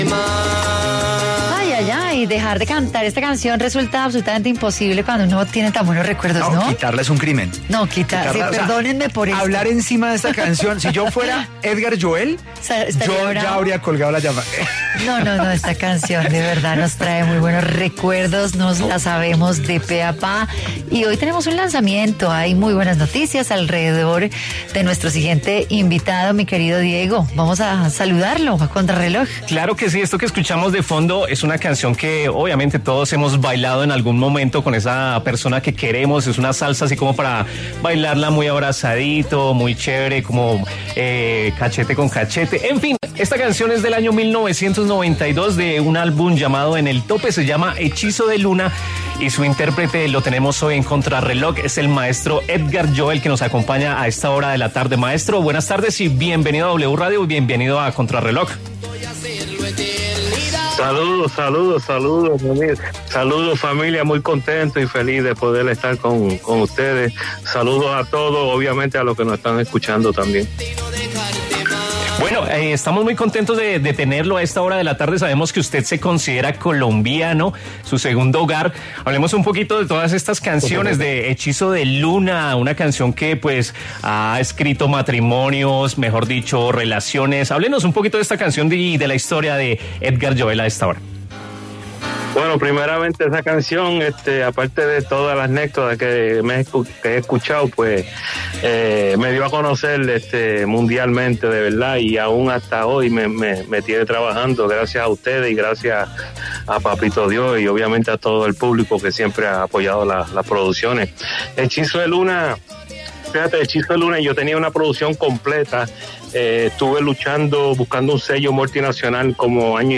El cantante de salsa habló a Contrarreloj sobre su trayectoria musical y del apoyo que siempre le ha dado su fanaticada.